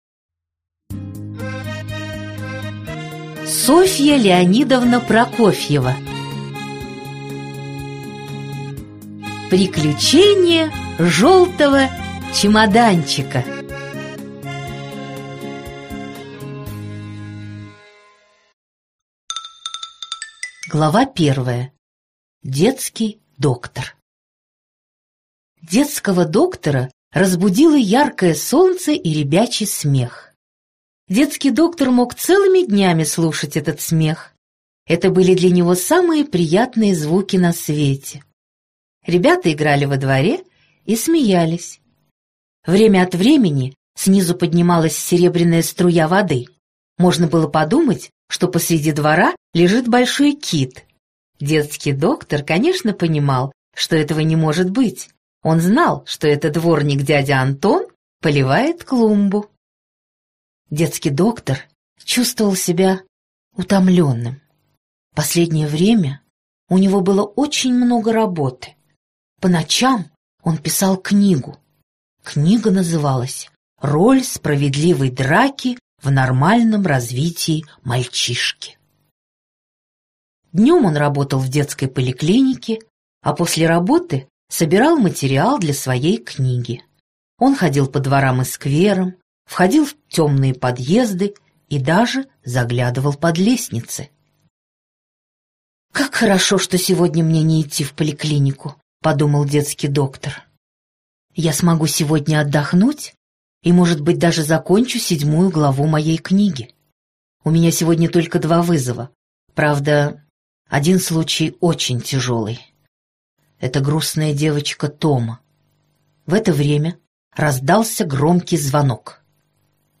Аудиокнига Приключения желтого чемоданчика. Сказочные повести | Библиотека аудиокниг